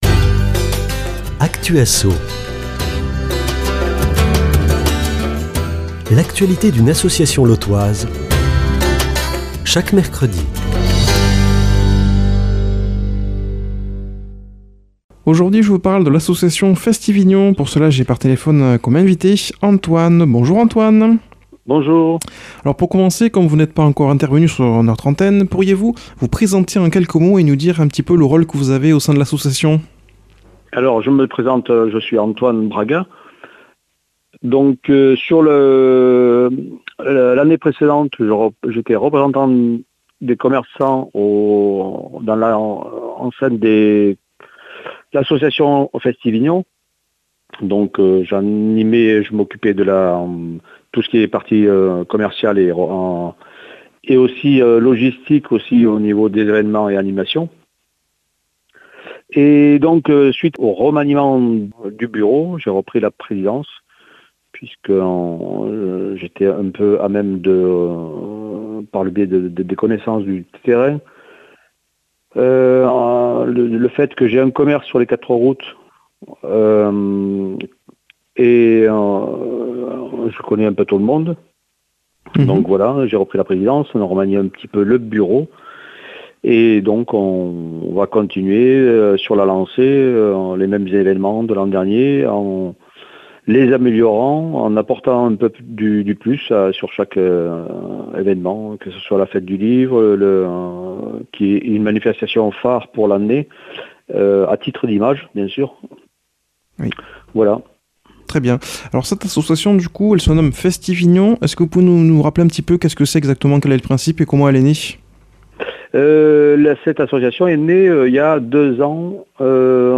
invité par téléphone